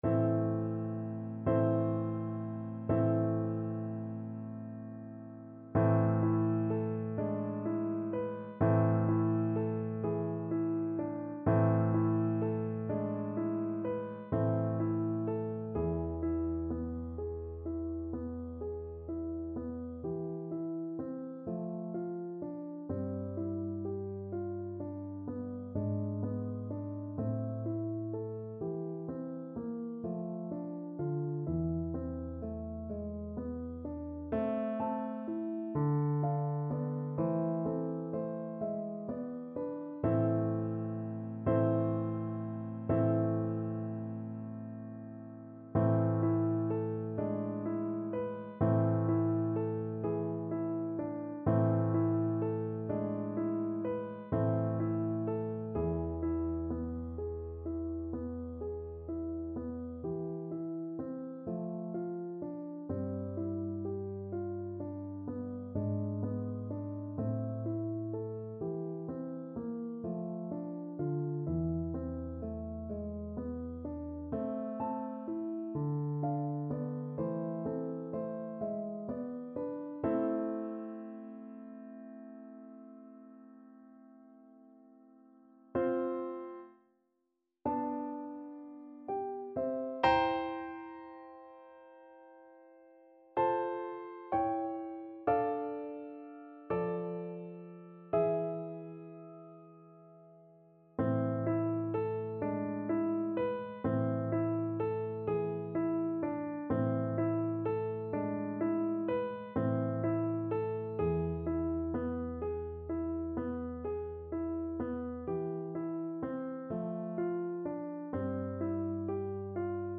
Play (or use space bar on your keyboard) Pause Music Playalong - Piano Accompaniment Playalong Band Accompaniment not yet available transpose reset tempo print settings full screen
6/8 (View more 6/8 Music)
A minor (Sounding Pitch) (View more A minor Music for Flute )
= 42 Andante con moto (View more music marked Andante con moto)
Classical (View more Classical Flute Music)